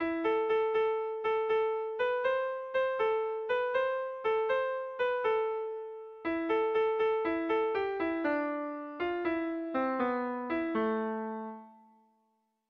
Kontakizunezkoa
10A / 10A / 10B / 8B
ABD